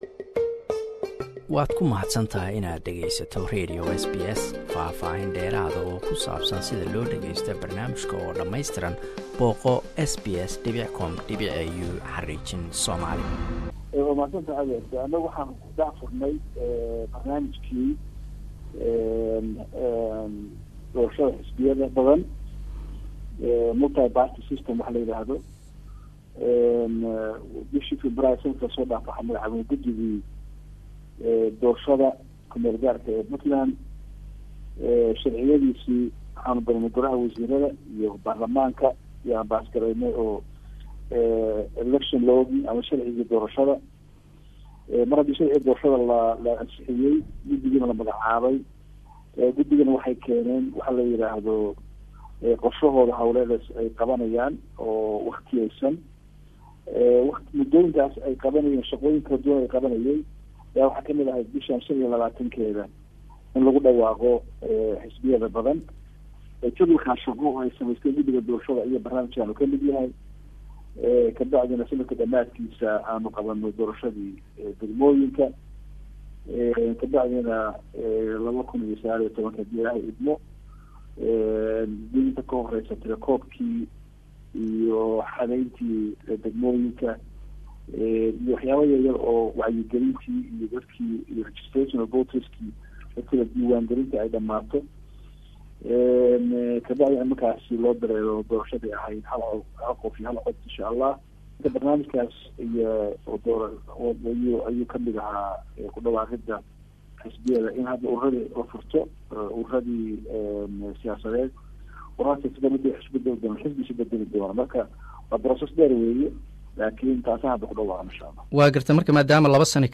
Interview: Puntland President Abdiwali Gaas